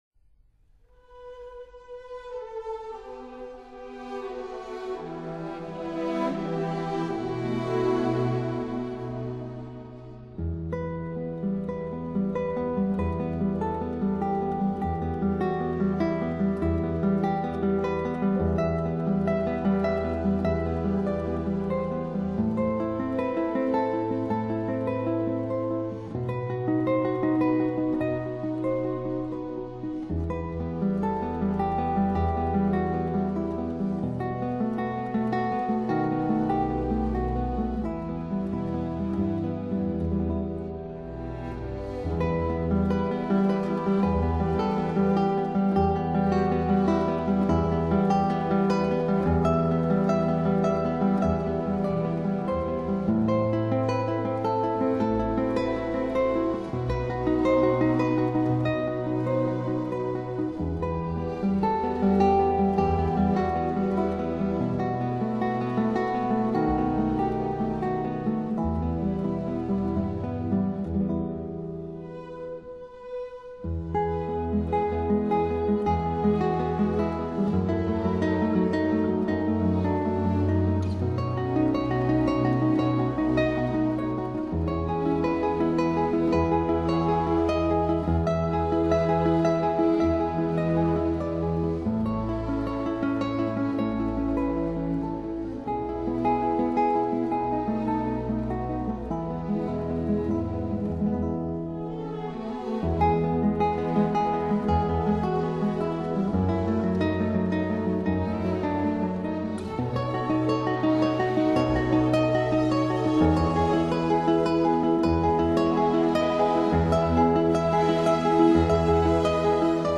Genre: Acoustic Classic Guitar Quality